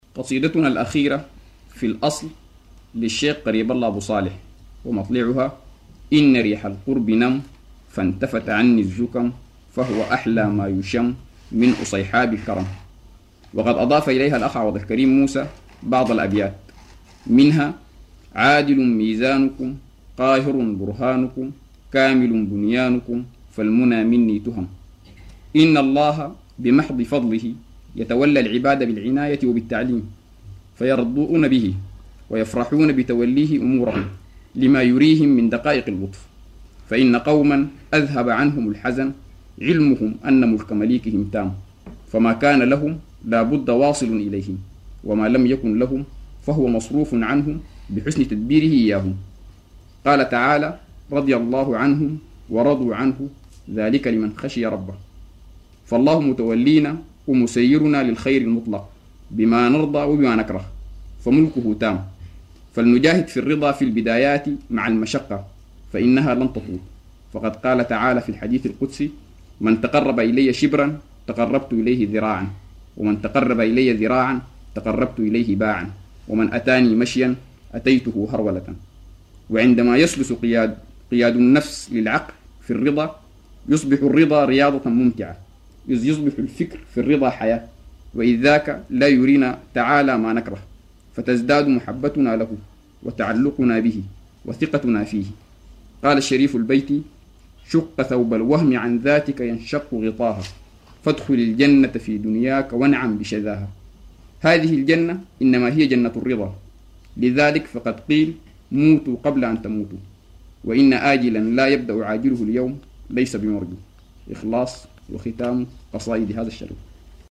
إنشاد
القصائد العرفانية